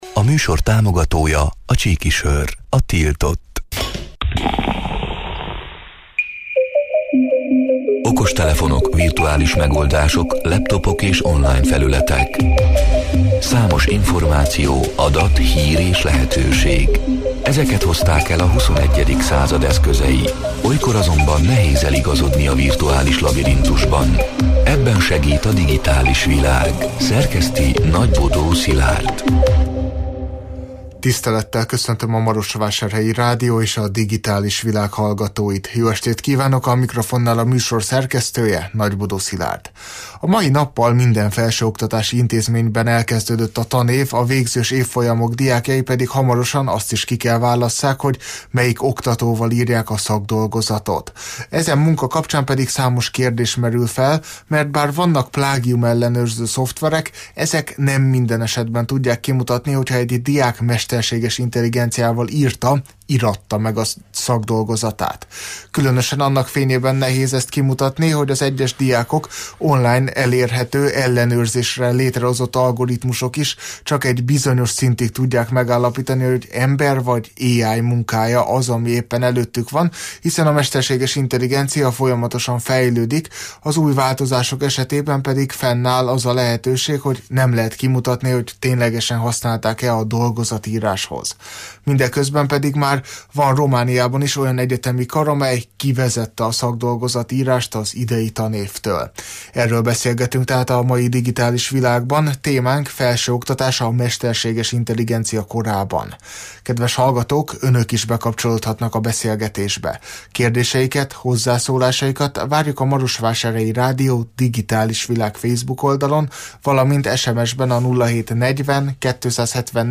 (elhangzott: 2024. október 1-én, kedden este nyolc órától élőben)